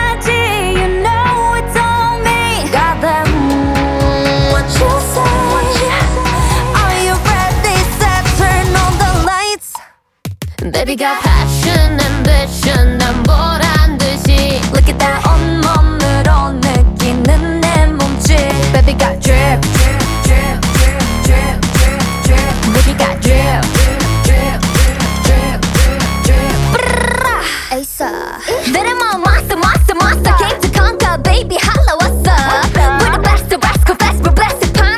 Жанр: Танцевальные / Поп / K-pop